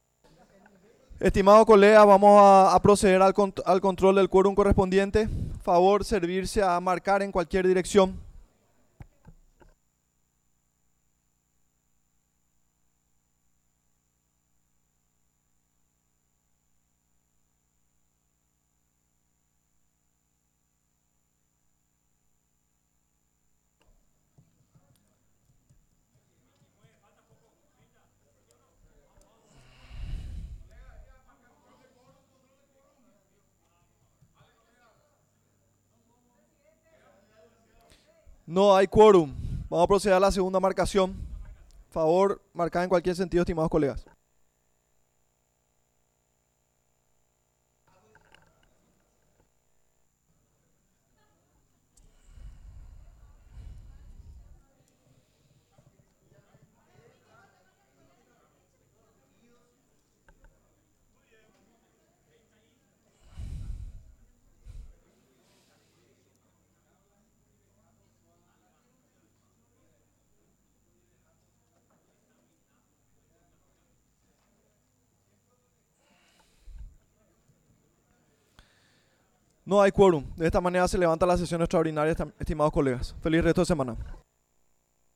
Sesión Extraordinaria, 20 de mayo de 2025